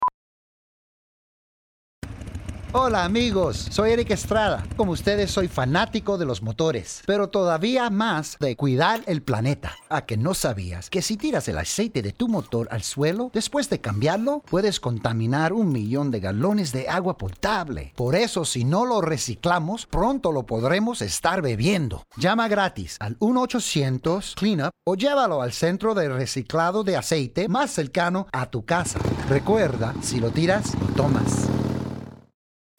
Used Motor Oil Public Service Announcements